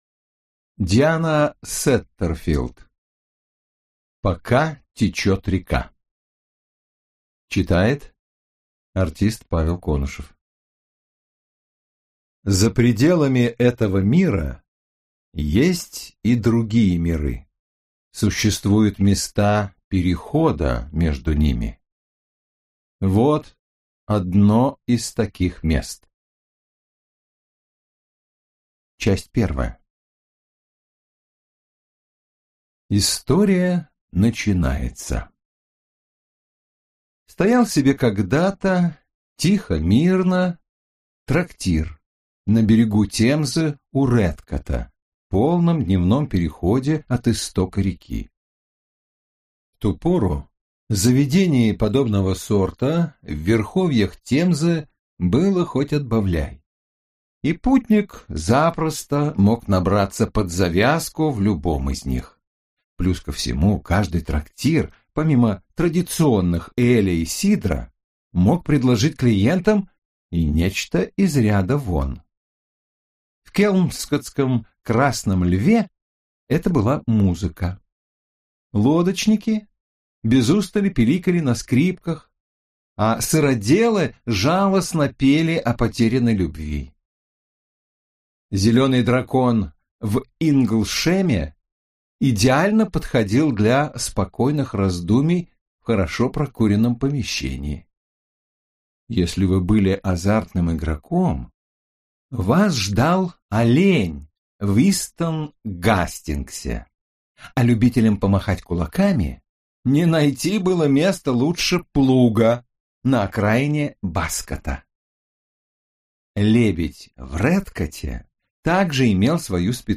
Аудиокнига Пока течет река - купить, скачать и слушать онлайн | КнигоПоиск